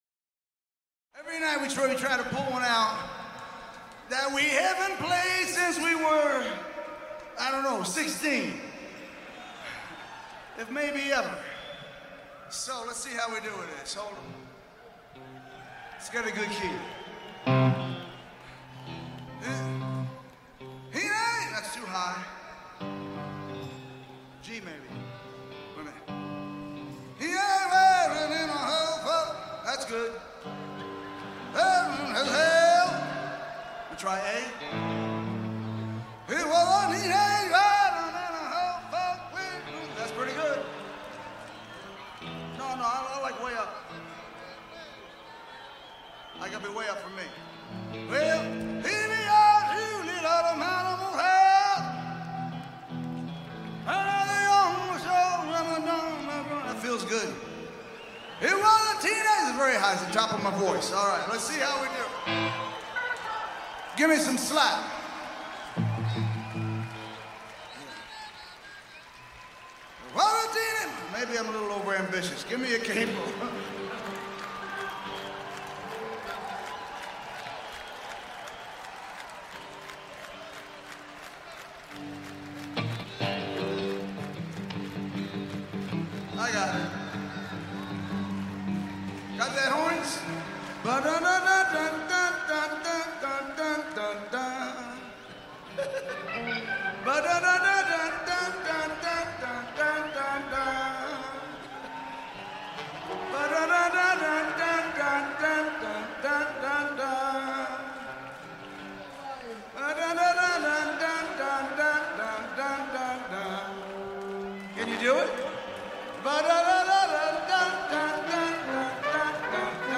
Leipzig 7_7_13